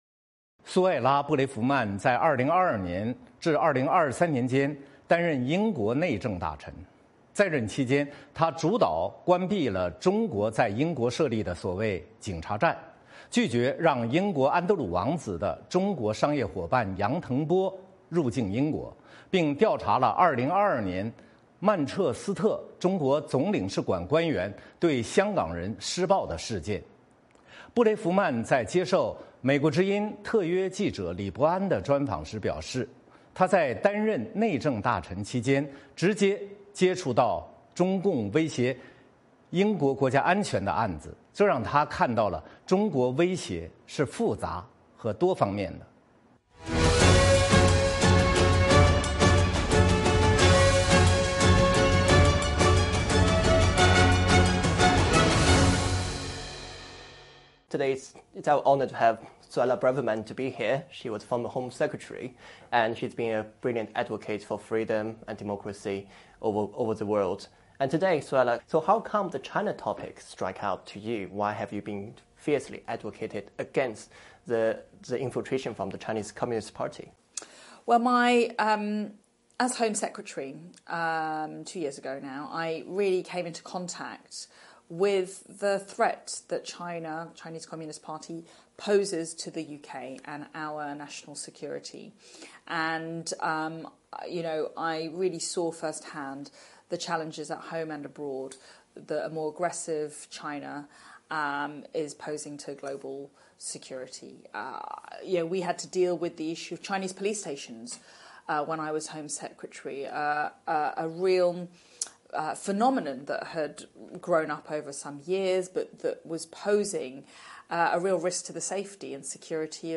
《纵深视角》节目进行一系列人物专访，受访者发表的评论不代表美国之音的立场 。